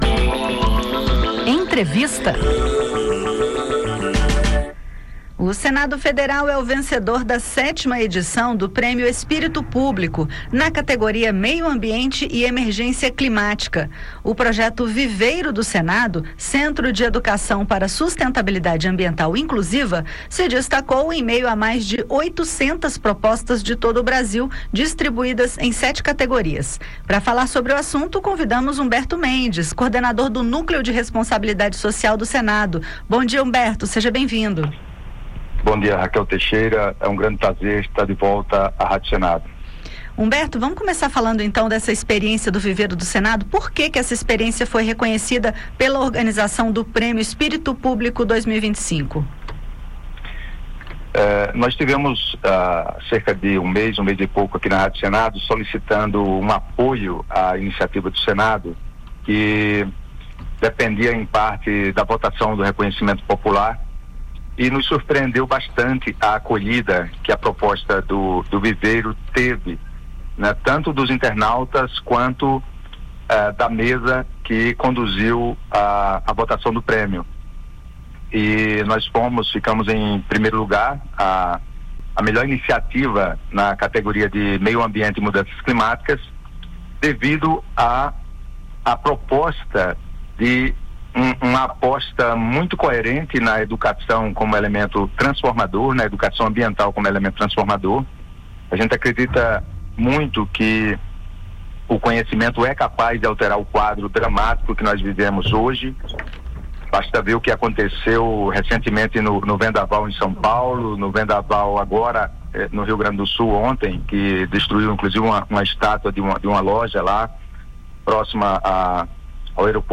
O Senado Federal venceu a 7ª edição do Prêmio Espírito Público, na categoria Meio Ambiente e Emergência Climática, com o projeto Viveiro do Senado. A iniciativa foi reconhecida entre mais de 800 propostas de todo o país pelo impacto social e ambiental. Para falar sobre o reconhecimento e os resultados do projeto, a Rádio Senado entrevista